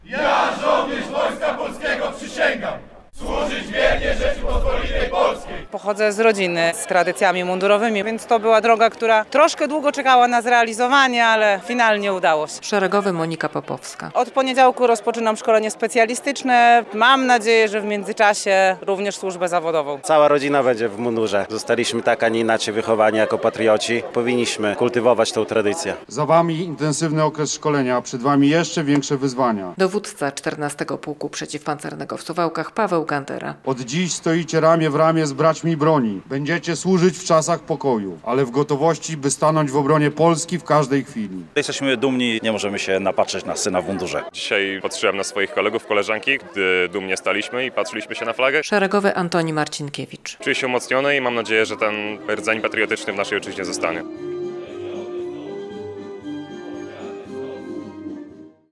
Ceremonia odbyła się na placu apelowym 14. Pułku Przeciwpancernego.